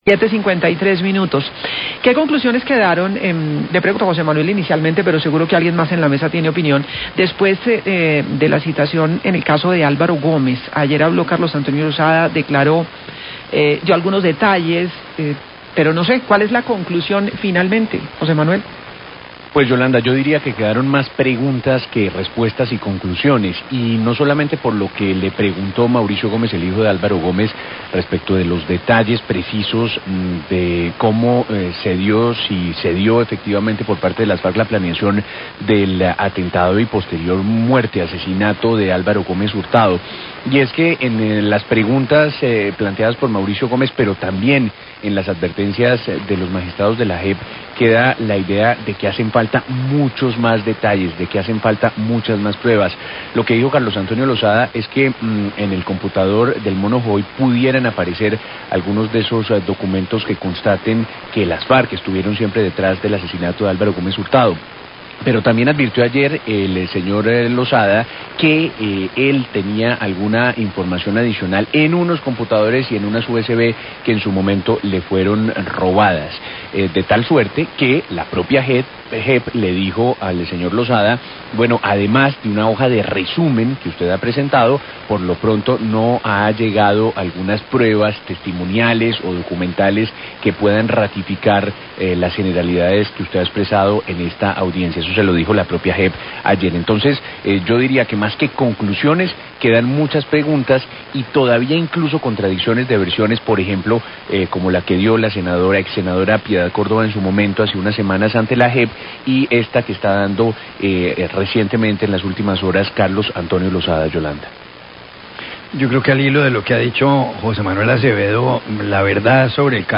Radio
Los periodistas del noticiero analizan las conclusiones tras escuchar la versión de Julián Gallo ante la JEP donde afirmó que en el computador del Mono Jojoy se encuentra datos sobre el asesinato de Álvaro Gómez. Dicen que quedaron más preguntas que respuestas tras los cuestionamientos de Mauricio Gómez, hijo del líder conservador asesinado.